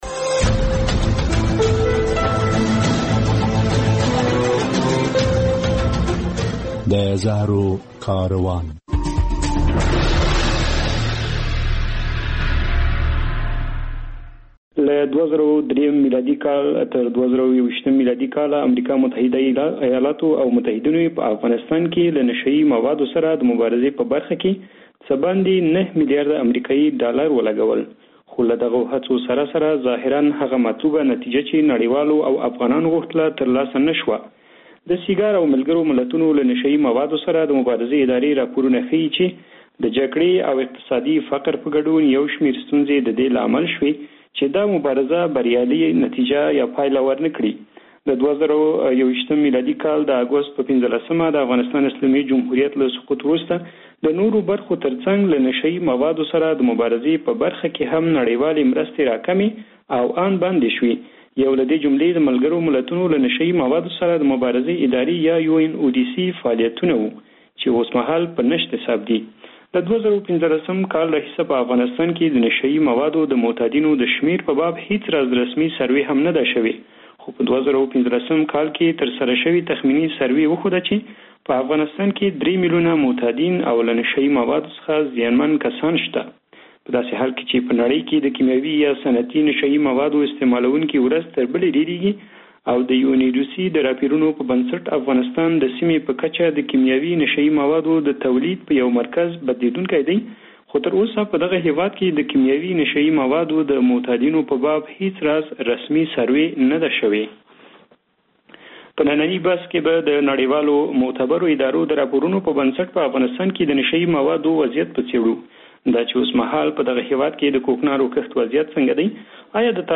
شنونکي وايي، په افغانستان کې د نشه يي موادو تولید زیات شوی دی. په دې خپرونه کې له میلمنو سره په افغانستان کې د نشه یي موادو د کښت، تولید، قاچاقو او استعمال په اړه خبرې شوي.